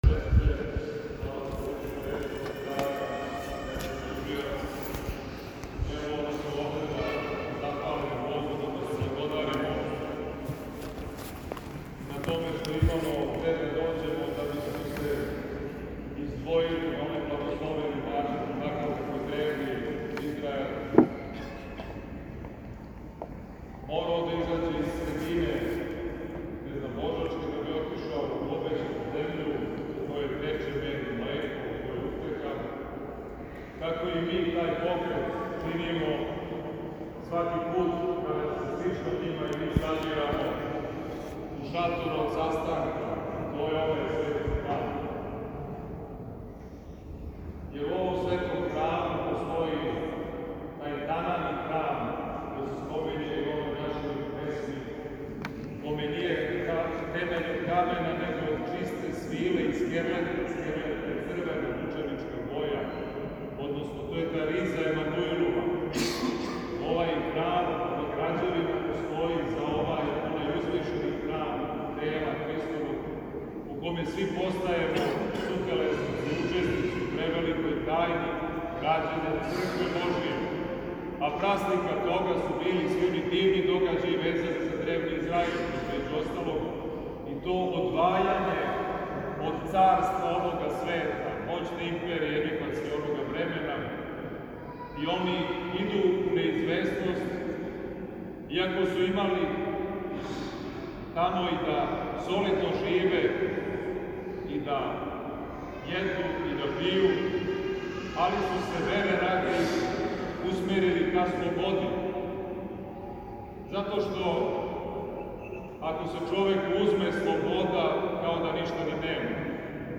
Његово Преосвештенство Епископ новобрдски г. Иларион служио је у недељу, 1. октобра 2023, свету Литургију у храму светог Марка на Ташмајдану. Звучни запис беседе